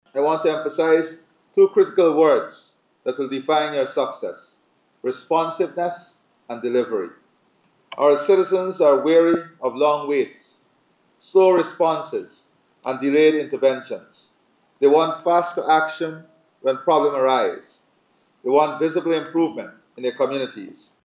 He made these remarks during Thursday’s swearing in ceremony for the Regional Chairpersons and Vice Chairpersons at the Arthur Chung Conference Center.